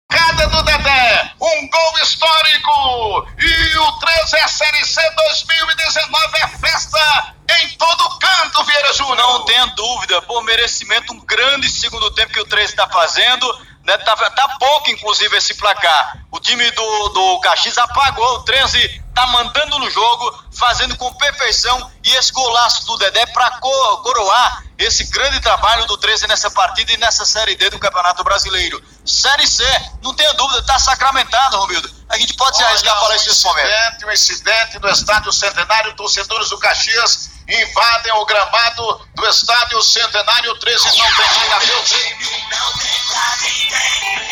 Ouça a emoção no gol da vitória do Galo da Borborema…